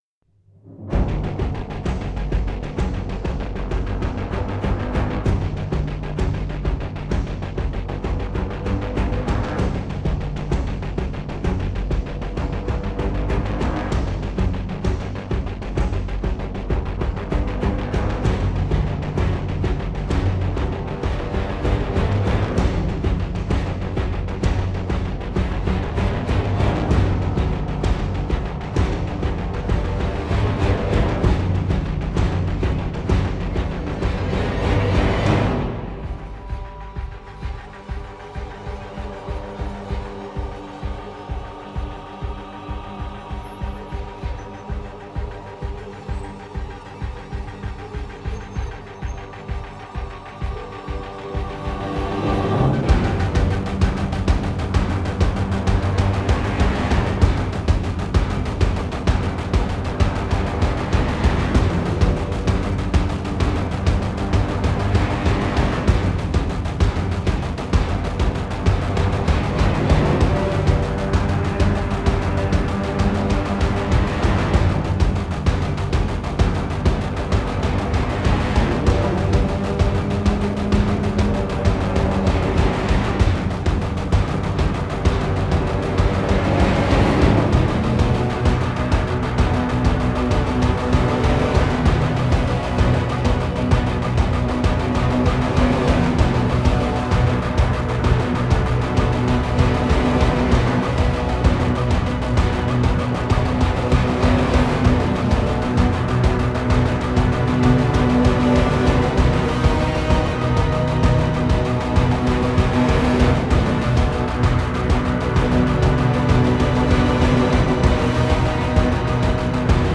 High-energy hybrid orchestral action!